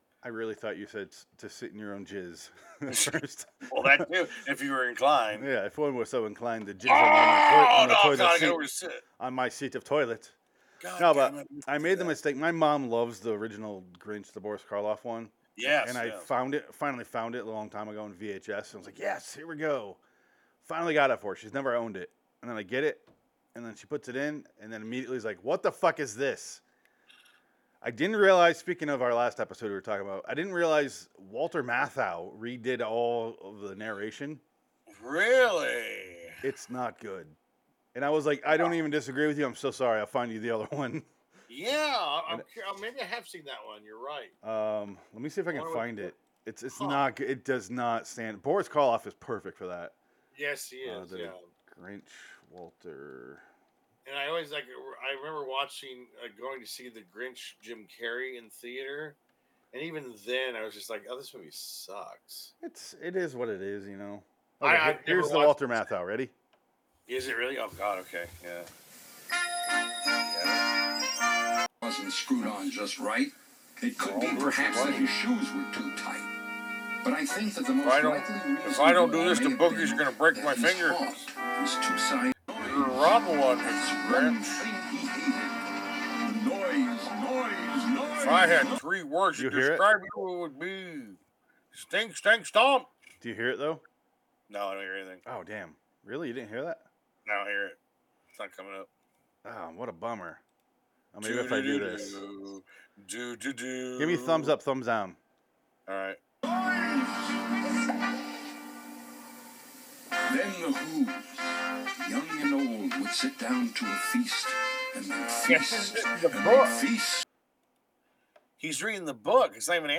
This show is stand-up meets review show.